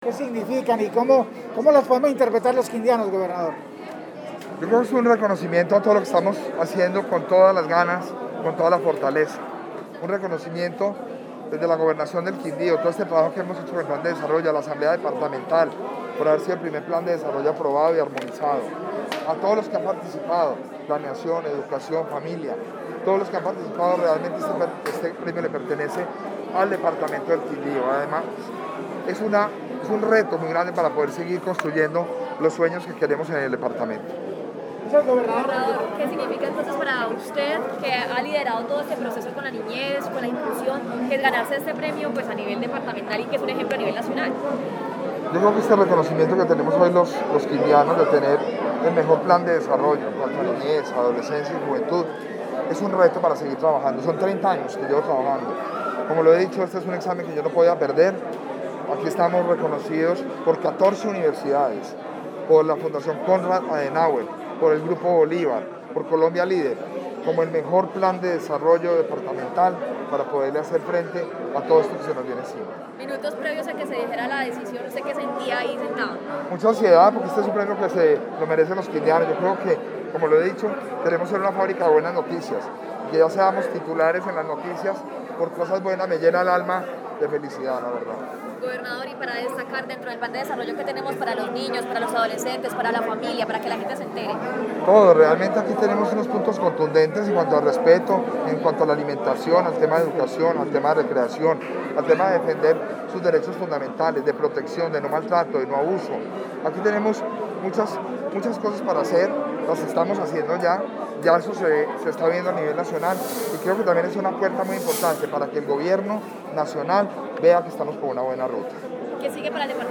Con gran emotividad, el gobernador Jaramillo Cárdenas agradeció a Colombia Líder y las entidades organizadoras, mientras que resaltó las líneas y documentos de los otros dos finalistas, Sucre y Putumayo, por lo que felicitó a sus mandatarios y equipos de trabajo, al reconocer que es una demostración del esfuerzo que se ha efectuado en las regiones para garantizar los derechos de nuestros niños, niñas y adolescentes.
Audio: Gobernador Roberto Jairo Jaramillo Cárdenas